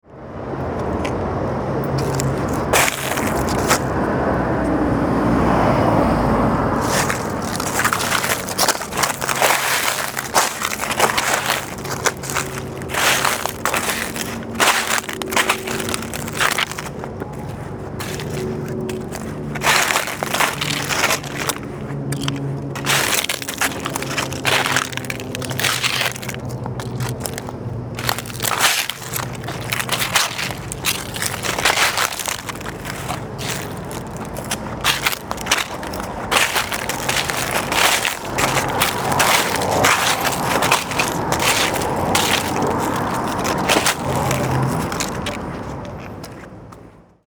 Marche, caniveau et bruit de feuilles
91_marche_caniveau_feuilles.mp3